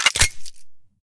select-machine-gun-3.ogg